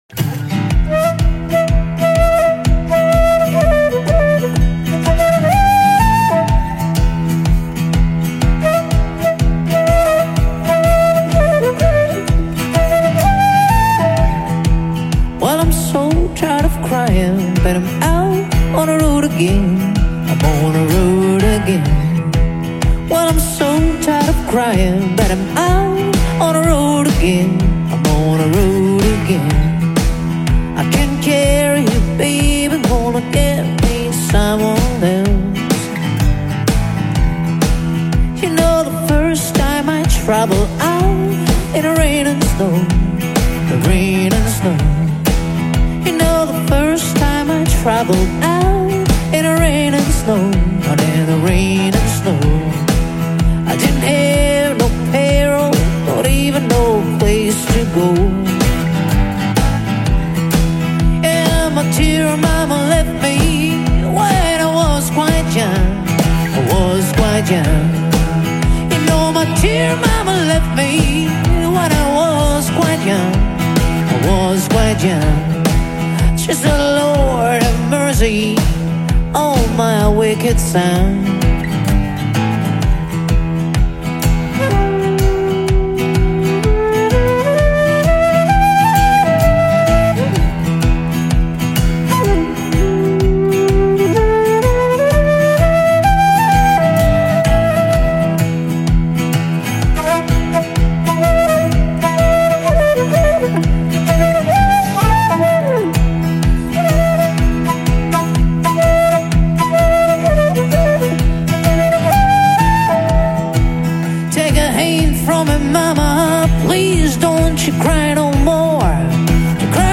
Standard Tuning - 4/4 Time